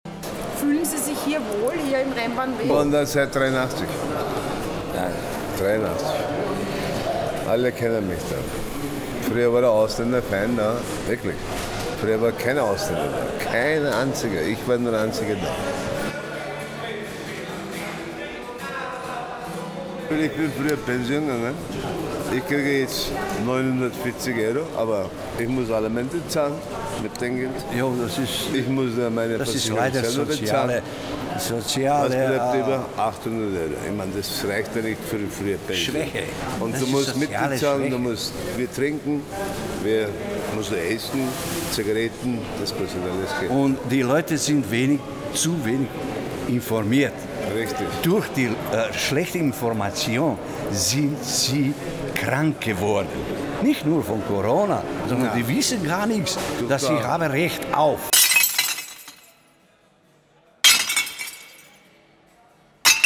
Soundcollage, Fotografie, künstlerische Forschung
Auch dieses Schweigen wird in die akustische Collage neben Field Recordings dieses großen, lebendigen, vielfältigen Soziotops eingearbeitet.